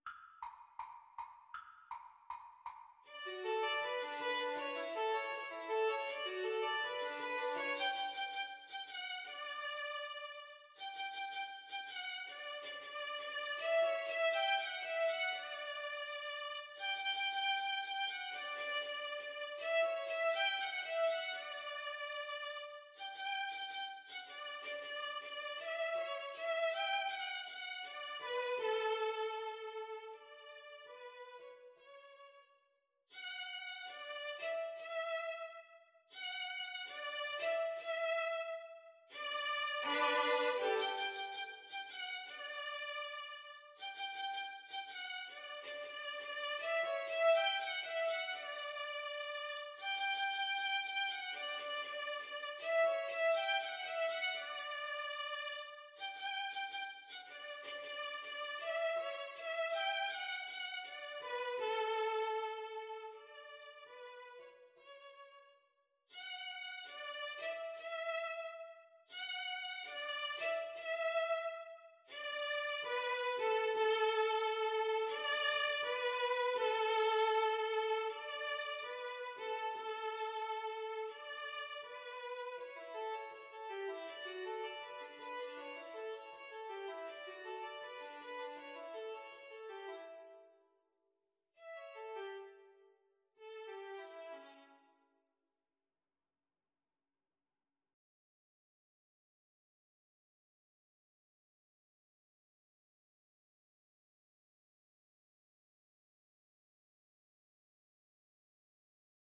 Free Sheet music for Violin Duet
4/4 (View more 4/4 Music)
D major (Sounding Pitch) (View more D major Music for Violin Duet )
Fast and bright = c. 160